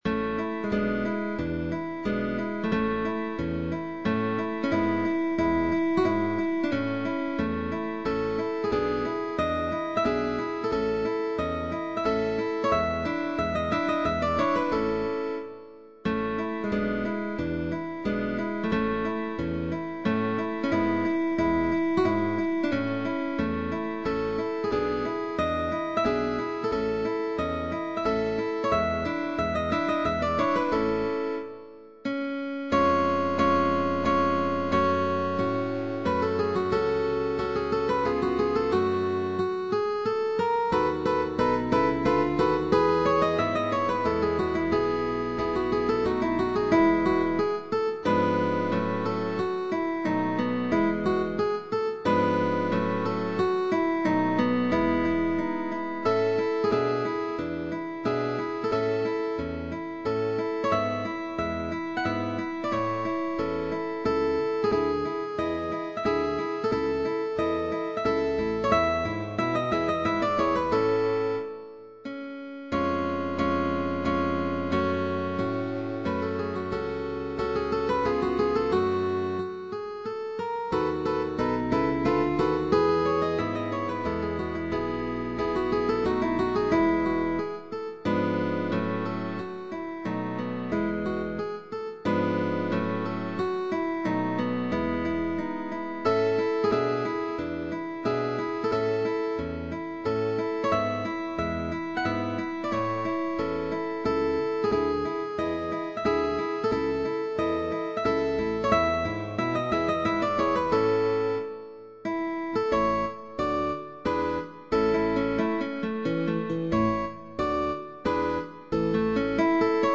CUARTETO de GUITARRAS
Con bajo opcional, válido para orquesta de guitarras.